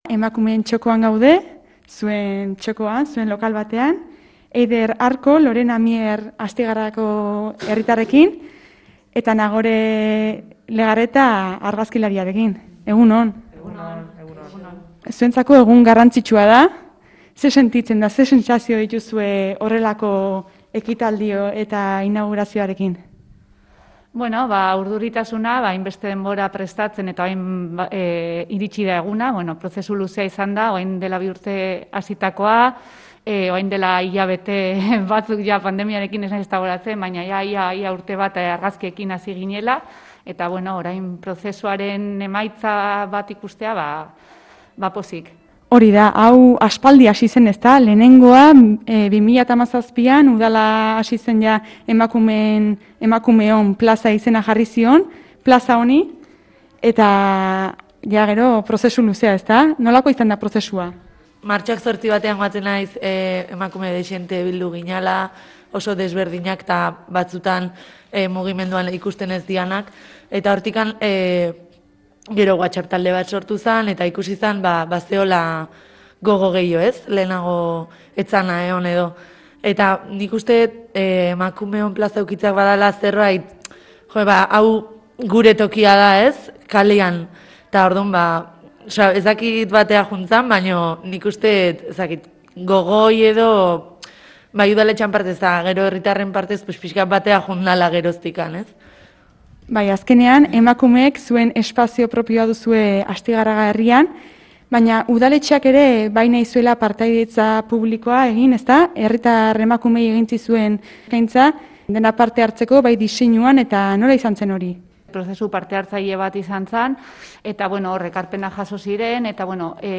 Emakumeon Plaza berriko inaugurazioa jendez lepo